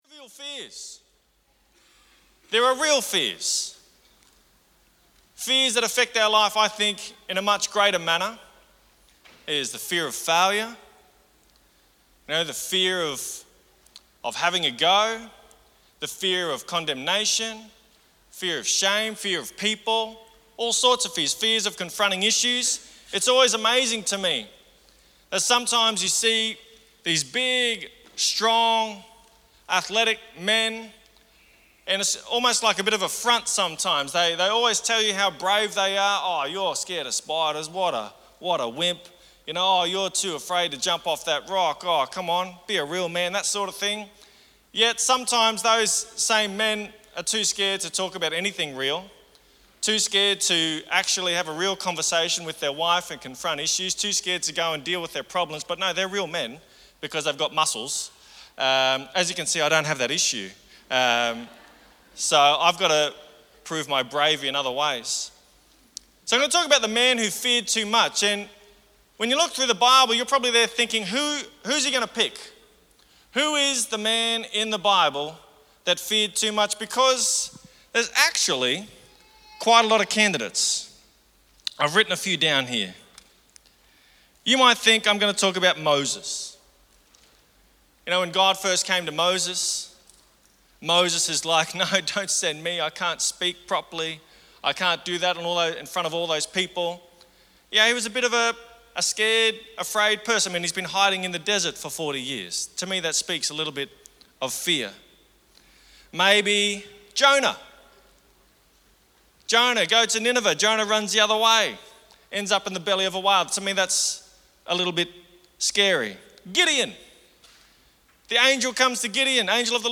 Sermons | Mackay Christian Family